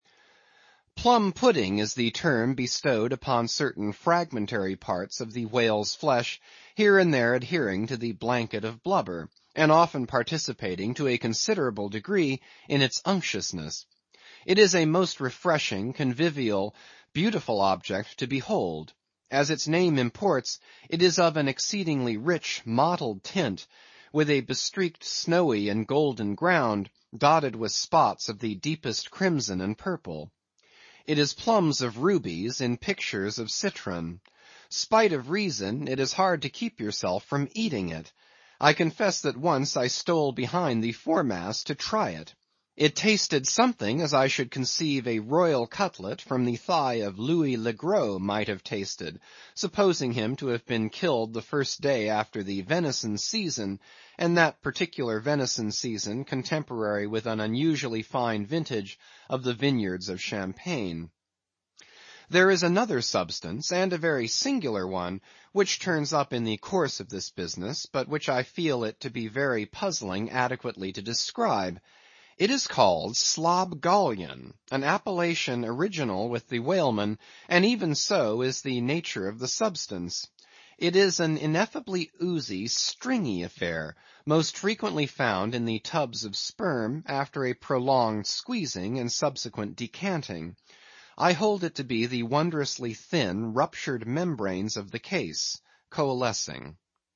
英语听书《白鲸记》第810期 听力文件下载—在线英语听力室